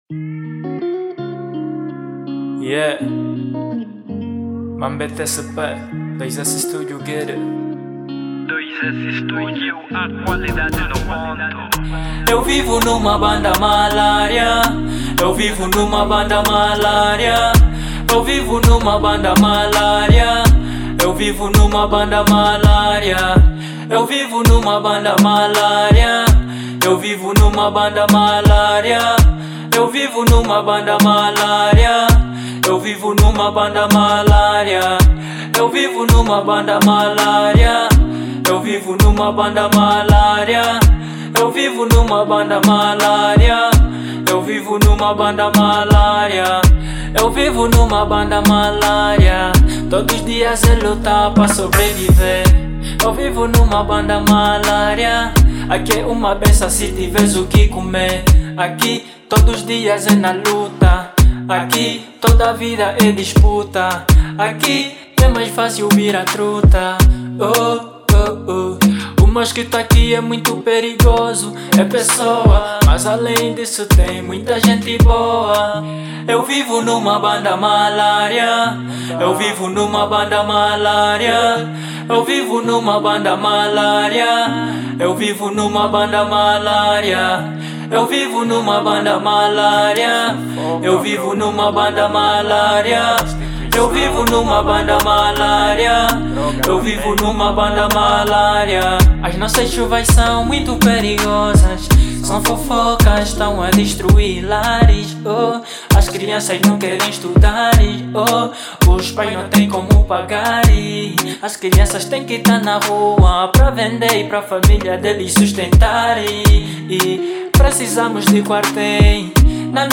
EstiloTrap